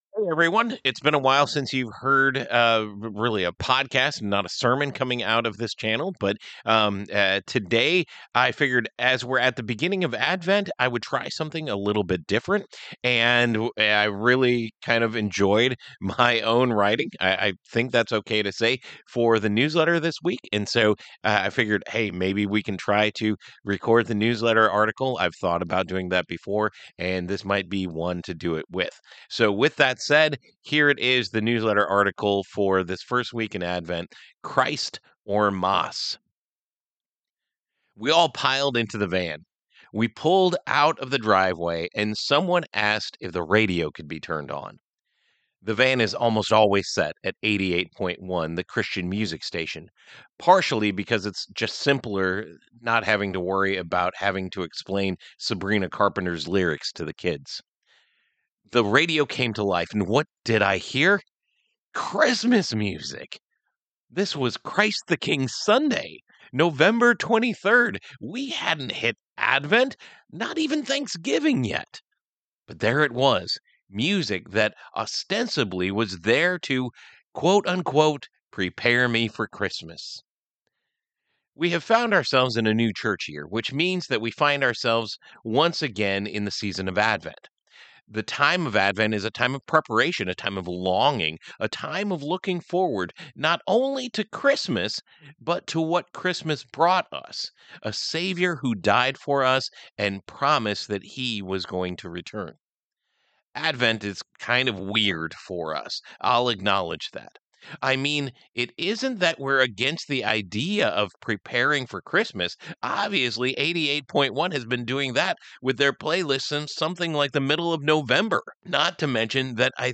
Trying something a little different and recording the newsletter article for this week.